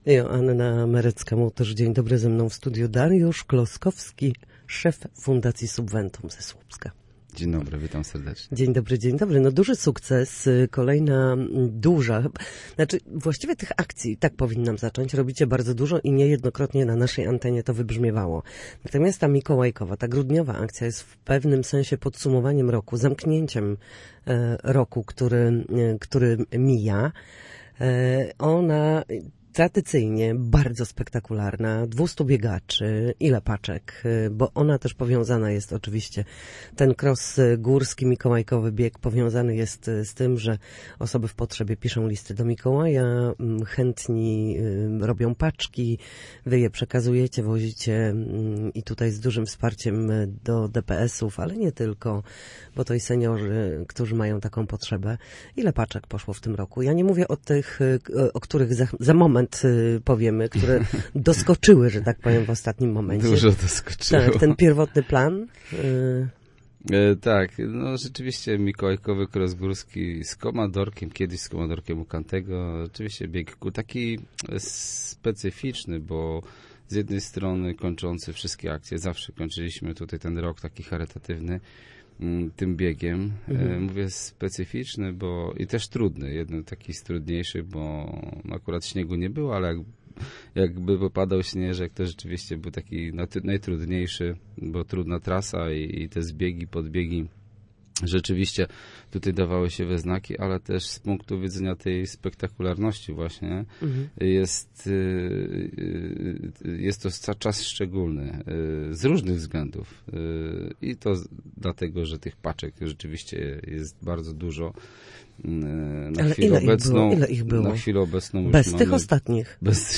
Na naszej antenie mówił o wsparciu, potrzebach najuboższych i wielkich sercach ludzkich.